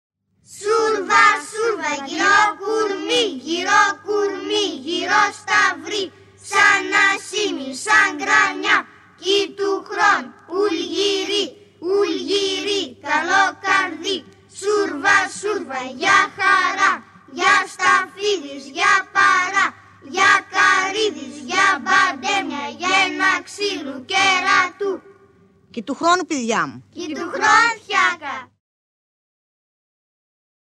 Στα χωριά του ‘Εβρου, τα παιδιά που λεν τα κάλαντα, κρατούν κι ένα κλαδί από σουρβιά (άλλο όνομα της κρανιάς), αειθαλές δέντρο με το οποίο χτυπούν στην πλάτη το νοικοκύρη λέγοντας: «Σούρβα σούρβα γιρό κορμί, γιρό κορμί, γιρό σταυρί….» άκουσε τώρα το παραπάνω πρωτοχρονιάτικο ρυθμικό τραγούδι που λέγανε τα παιδιά στην περιοχή του Έβρου.